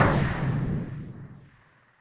cannon.ogg